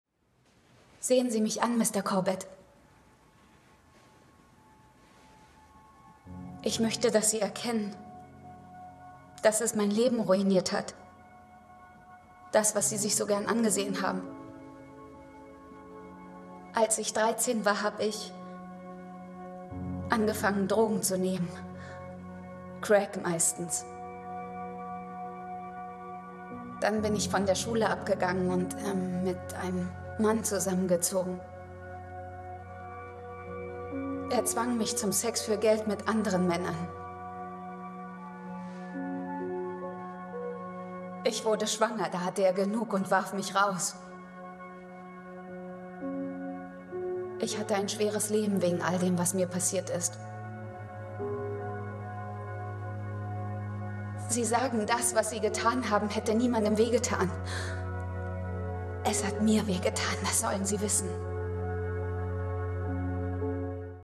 Stimmen Synchron - Suburra (Serie) Synchron - Law & Order 2 (als Jenny, EpHR) Gesang (klassisch) - Arie aus Rinaldo von G.F. Händel Podcast / Off - Der Fluch des Rings (Spotify)